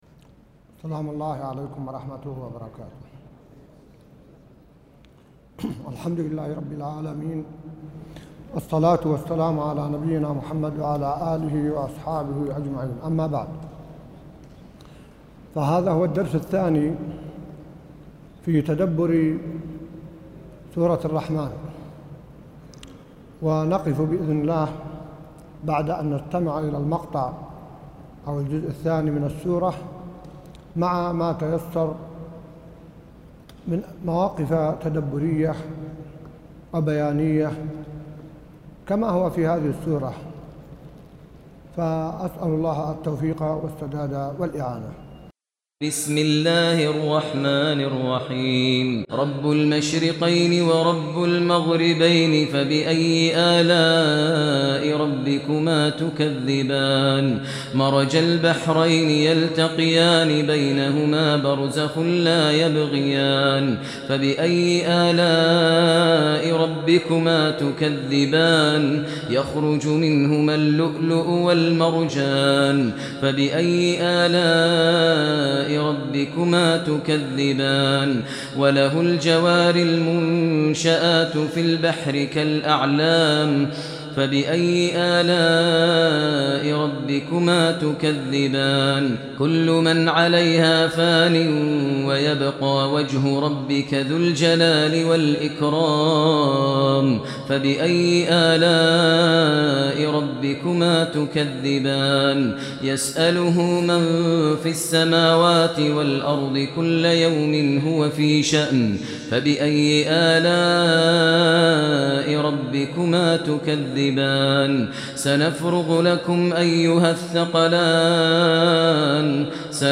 درس ليدبروا آياته 94 | وقفات تدبرية مع سورة الرحمن الجزء الثاني | موقع المسلم